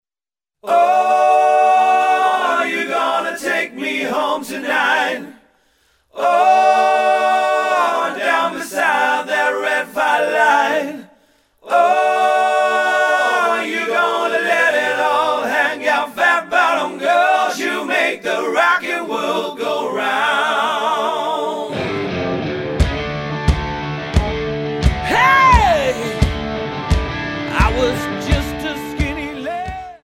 Tonart:D Multifile (kein Sofortdownload.